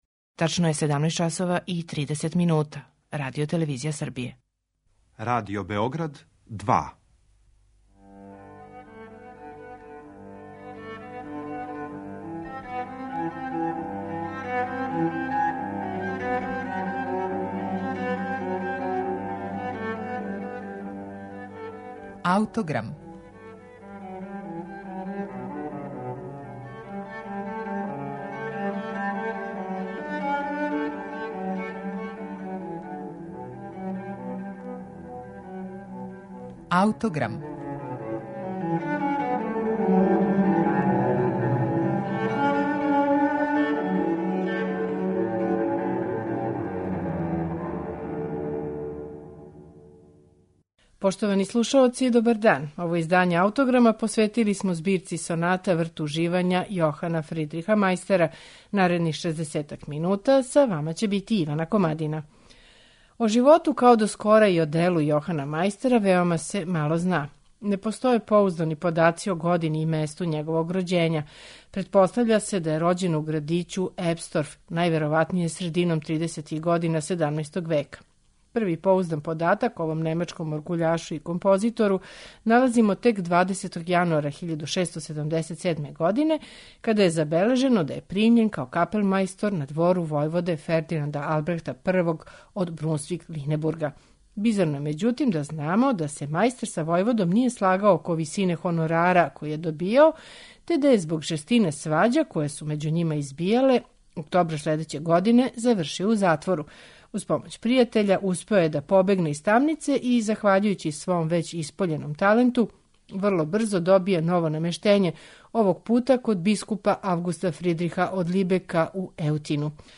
трио-соната
виолиниста
виолончелисте
чембалисте